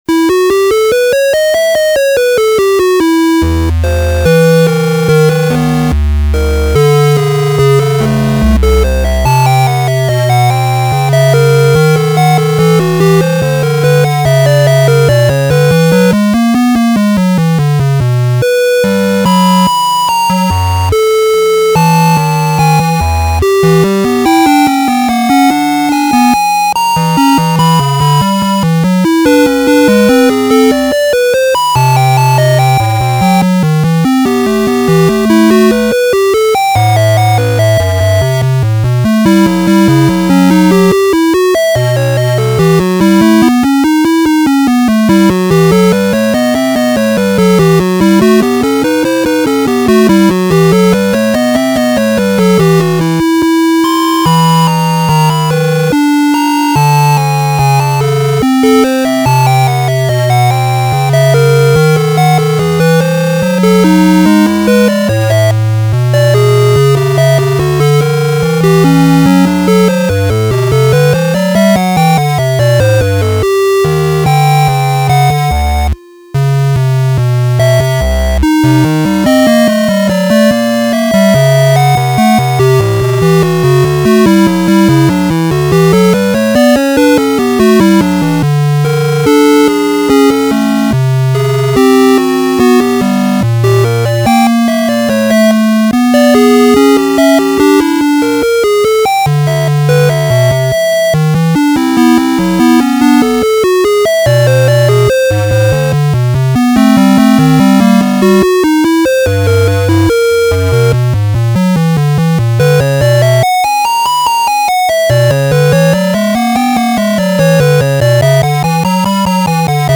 Feedback on Chiptune Music
I have been messing around with the ES1 synth in Logic and I just made this chiptune music.
This music is kind of spooky to me! It reminds me of Halloween music!
Interesting panning, but the panning is kinda nauseating, sorta could even slightly in the balance though.
The chiptune sound quality matches though.
Yeah, I just panned each track left and right.
It sounds like a old 8 bit super mario music for that ghost place
It sounds like random beeps put together in hopes of making a melody.